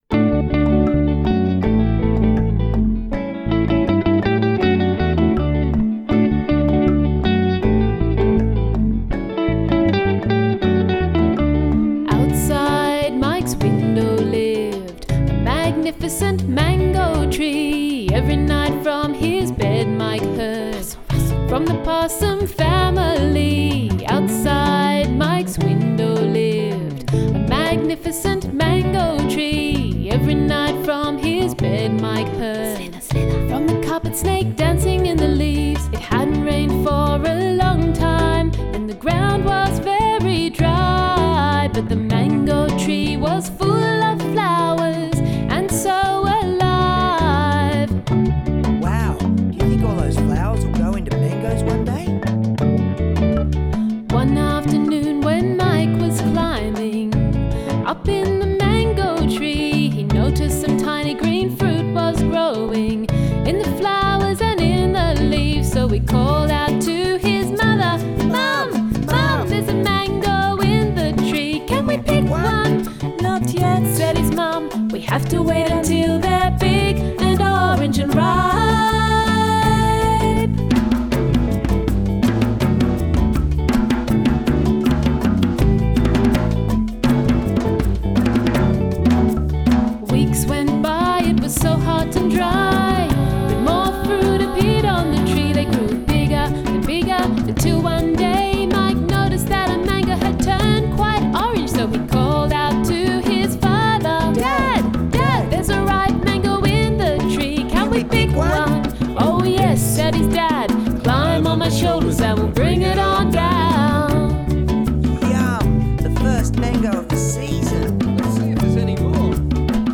parent friendly children’s album